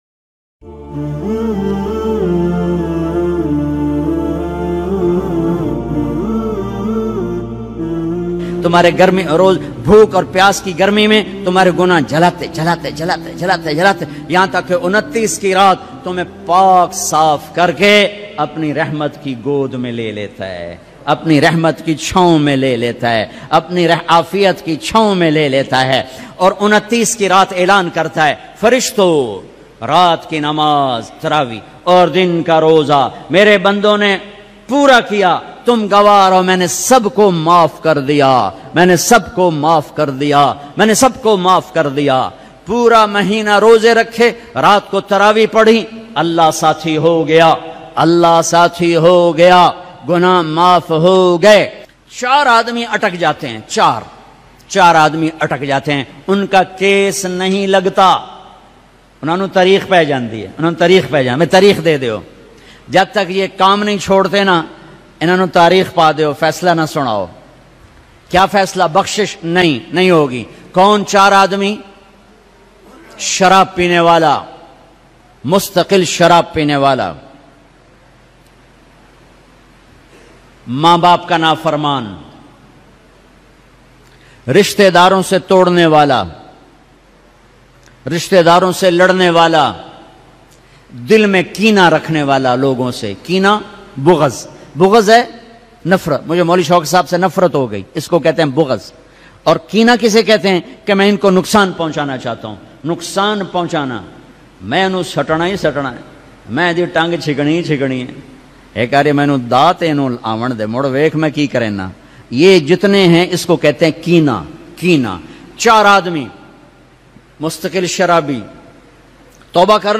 4 Logo Ki Toba Qabol Nahi Hugi Lattest Ramzan Bayan